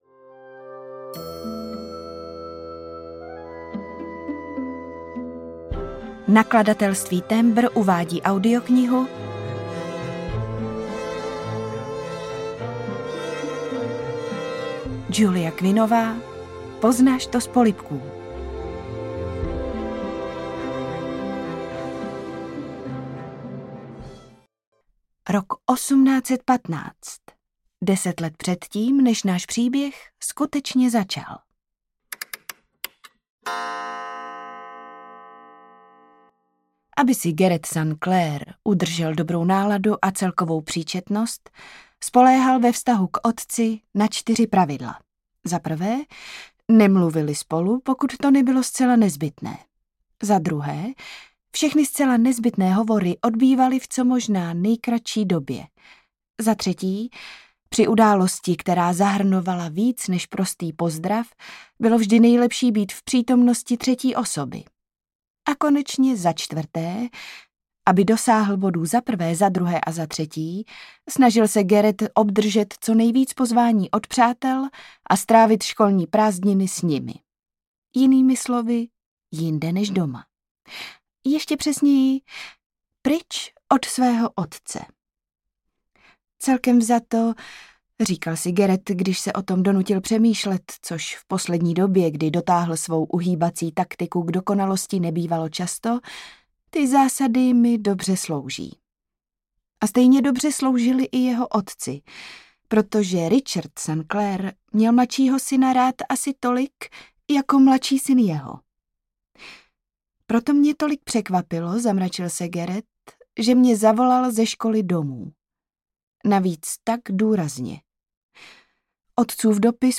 Poznáš to z polibků audiokniha
Ukázka z knihy